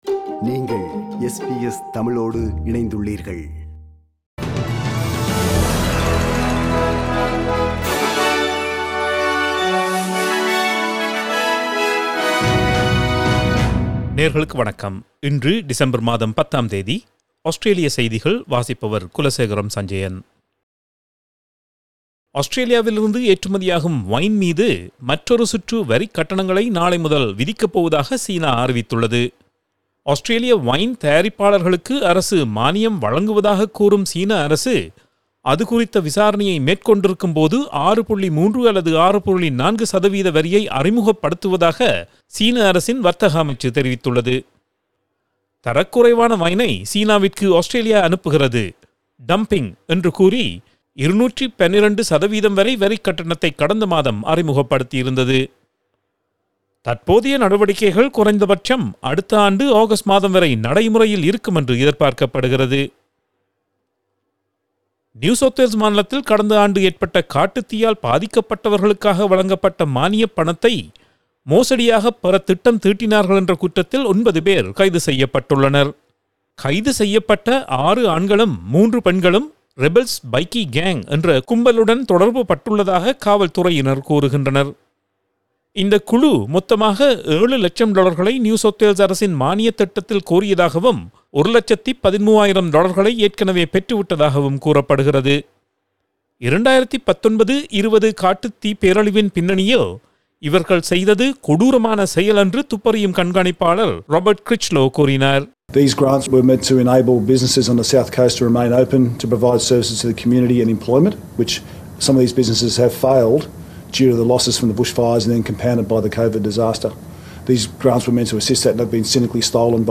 Australian news bulletin for Thursday 10 December 2020.